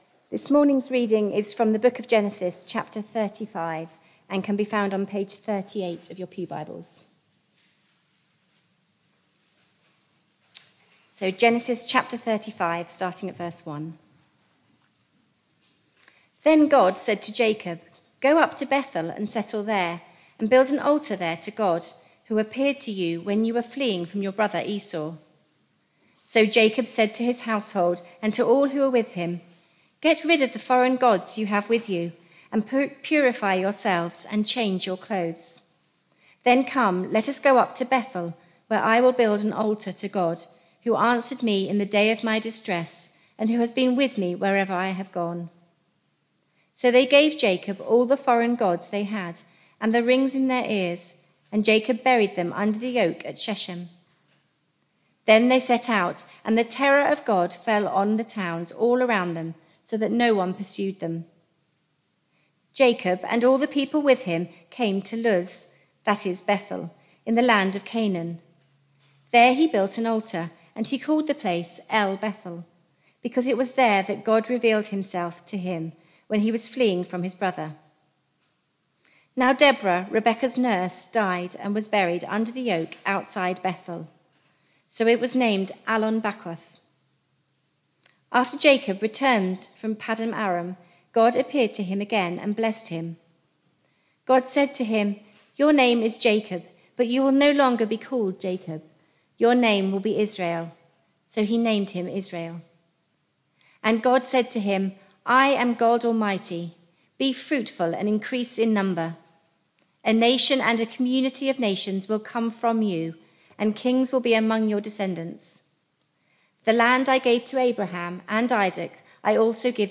Barkham Morning Service
Reading and sermon